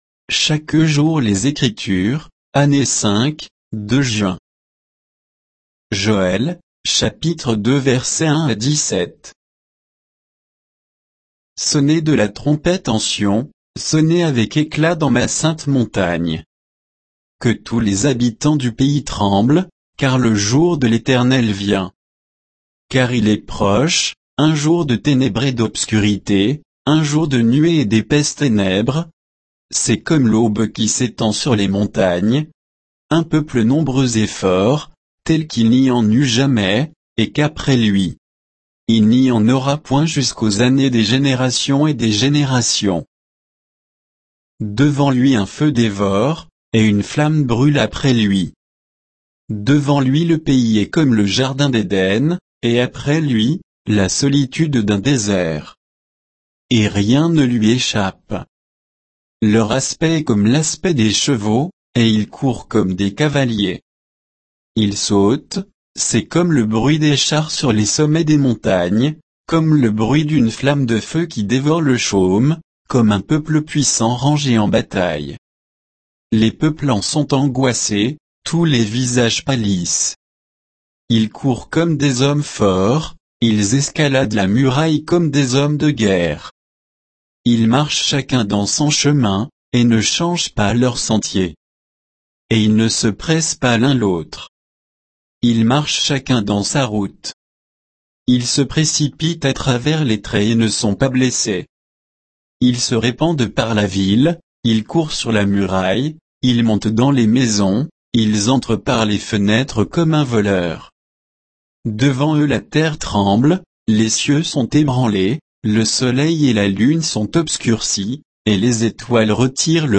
Méditation quoditienne de Chaque jour les Écritures sur Joël 2